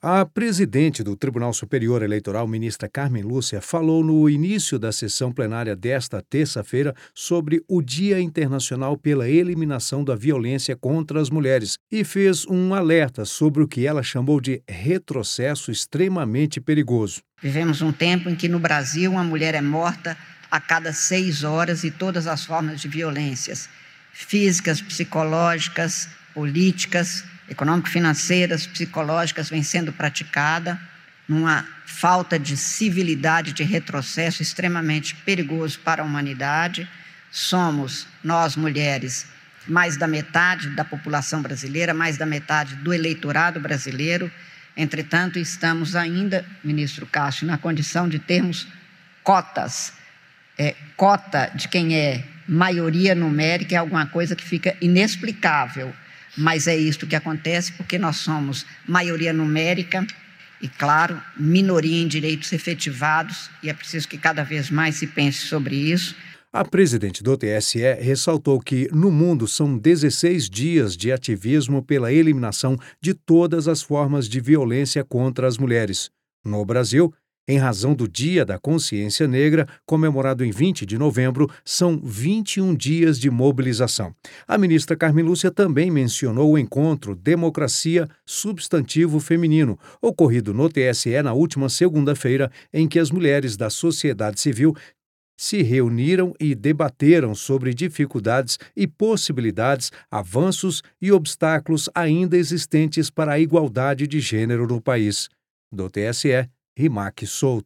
A presidente do Tribunal Superior Eleitoral, ministra Cármen Lúcia, fez um alerta sobre o retrocesso no combate à violência contra as mulheres, durante sessão que marcou o Dia Internacional pela Eliminação da Violência contra as Mulheres. Ela destacou os 21 dias de ativismo no Brasil e a importância da igualdade de gênero na democracia.